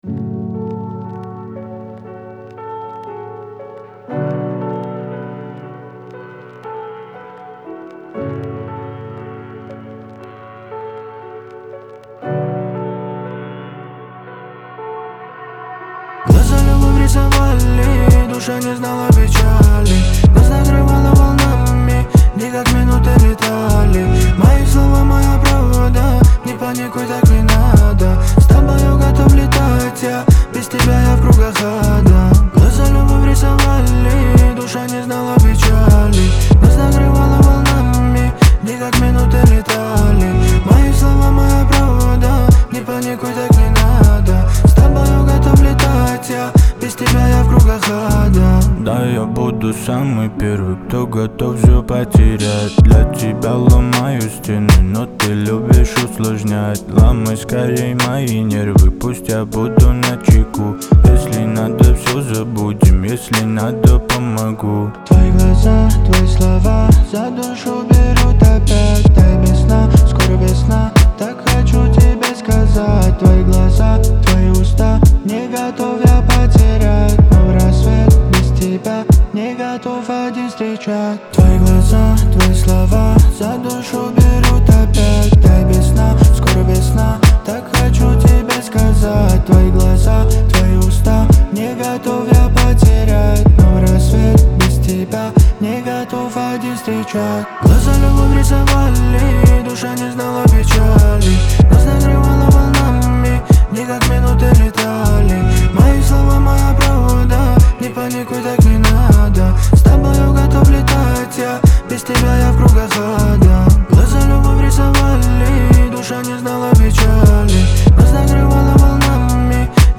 Трек размещён в разделе Русские песни / Рэп и хип-хоп.